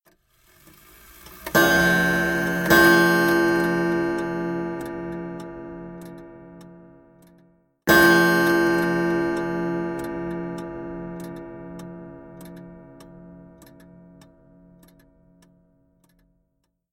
2-1 Chime
Tags: clock